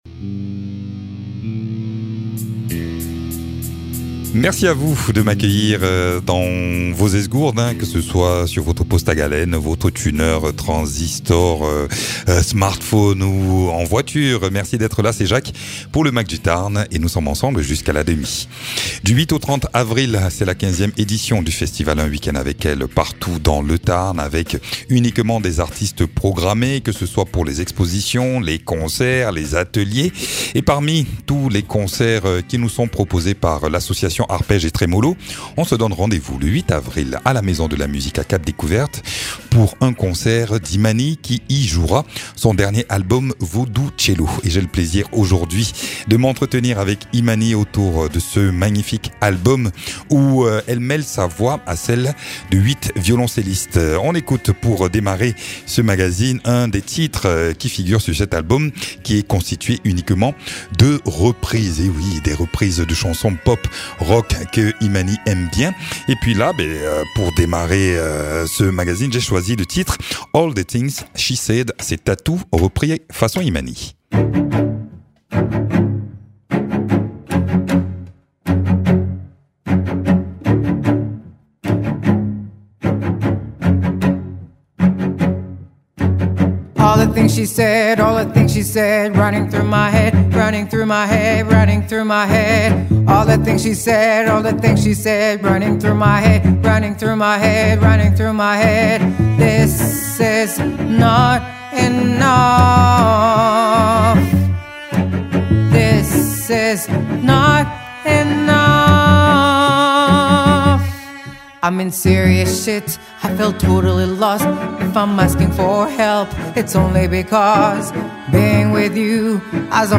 Invité(s) : Imany, chanteuse-auteure-interprète.